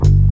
G_07_Bass_05_SP.wav